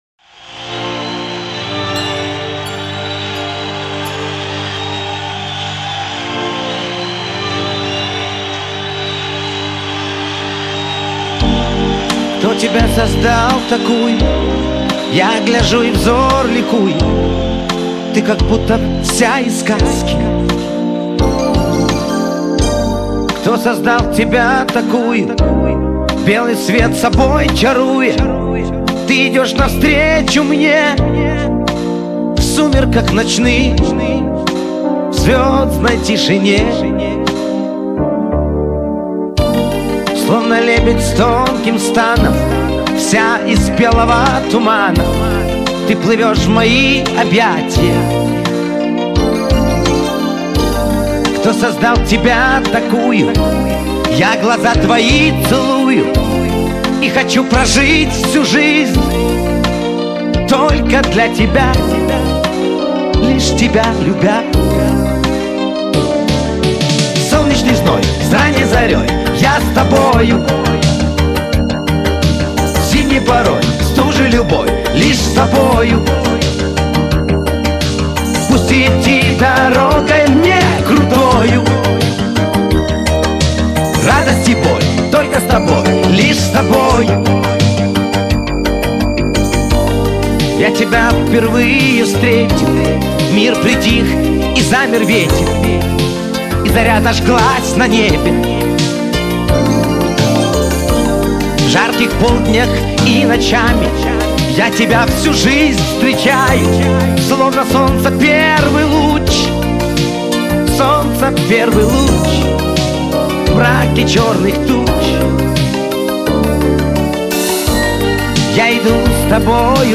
Всі мінусовки жанру Ballad
Плюсовий запис